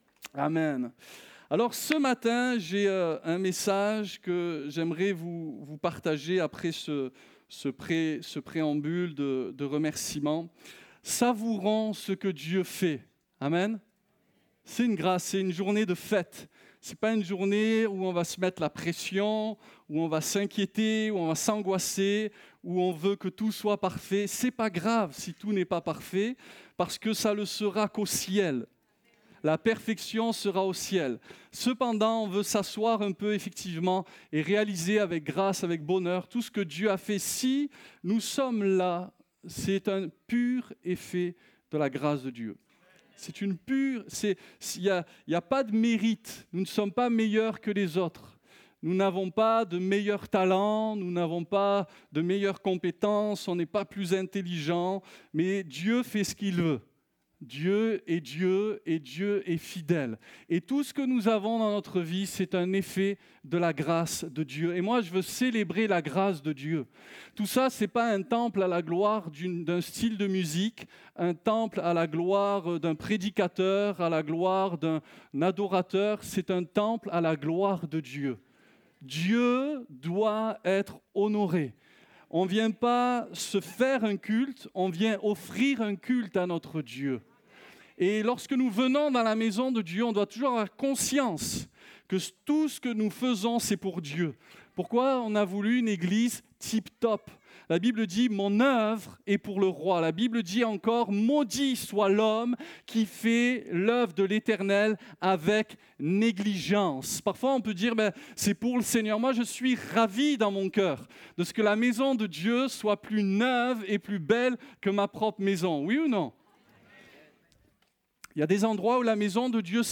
Découvrez en replay vidéo le message apporté à l'Eglise Ciel Ouvert
Réunion: Culte